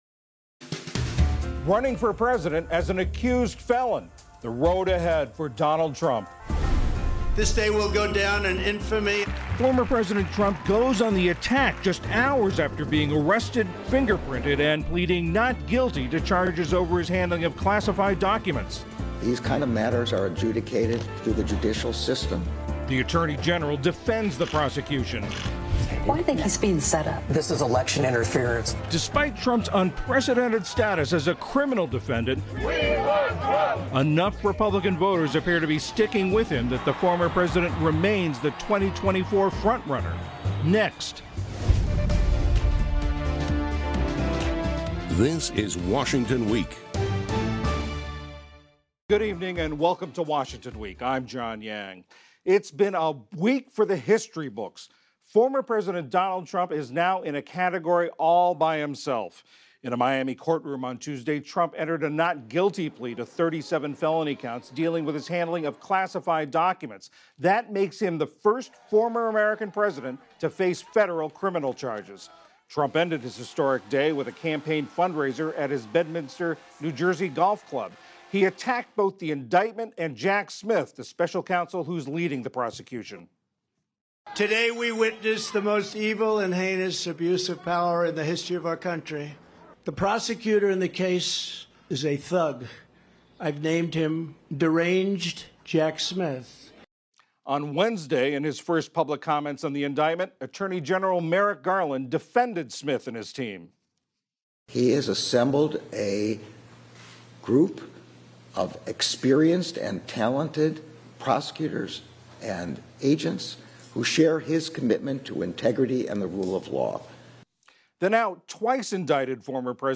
News, News Commentary, Politics